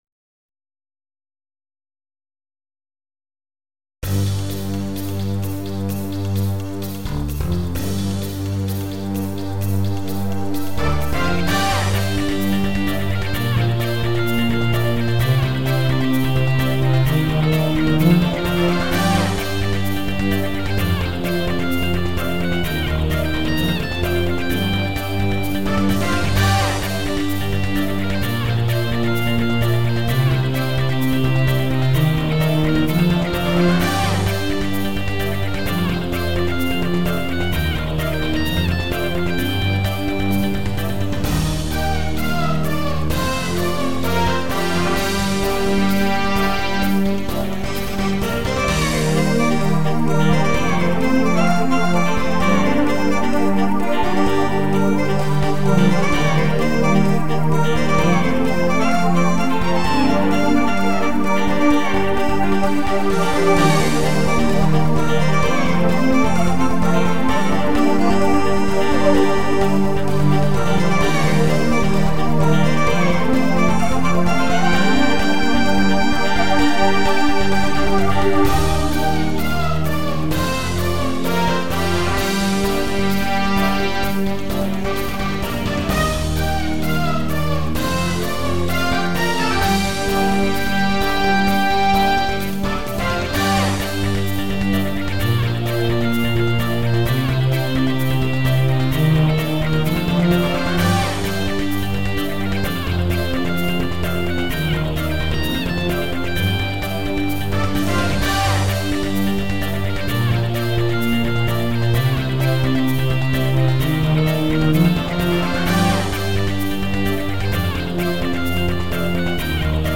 1 A song that starts after a few seconds, so don't quit after the first 2 seconds thinking there's no sound